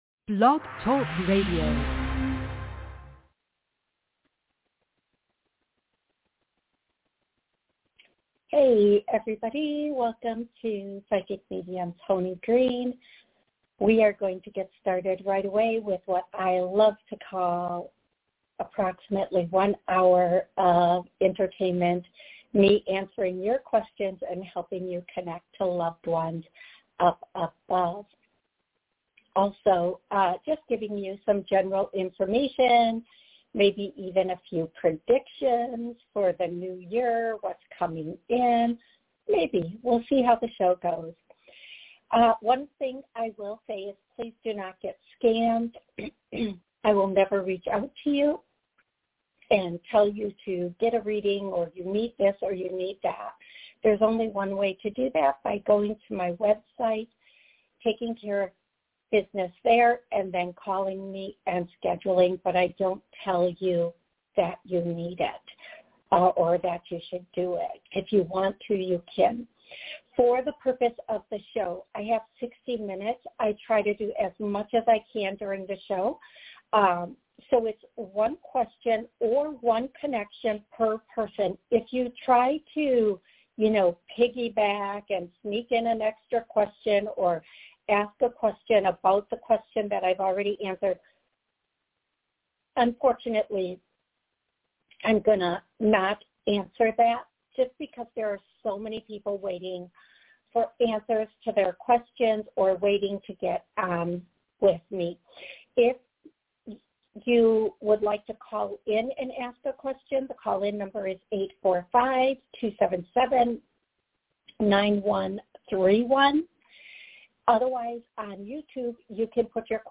Live Readings